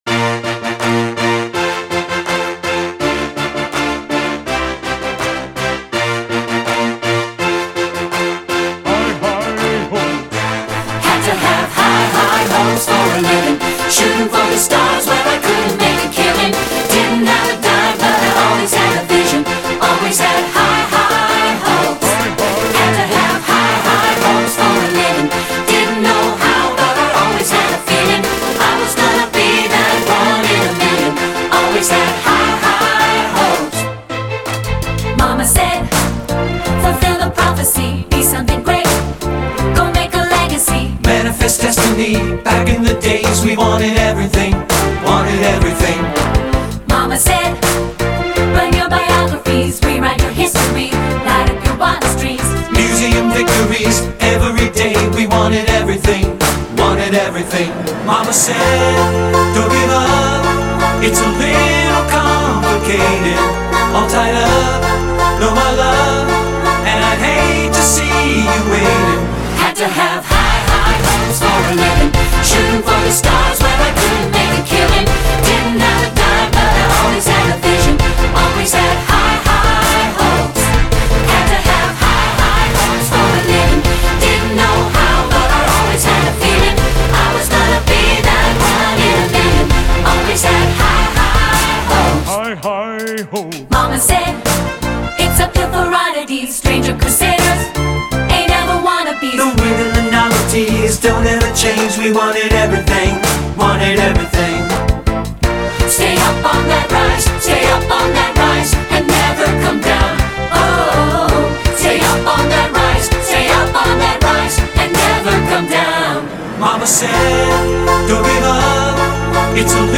Voicing: SAT(B) and Piano